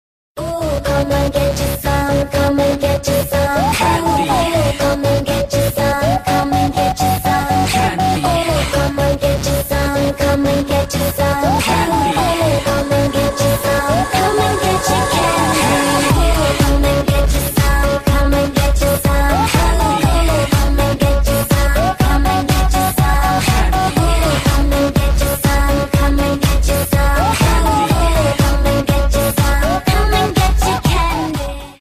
• Качество: 128, Stereo
мужской голос
громкие
женский вокал
красивая мелодия
Dance Pop
Electropop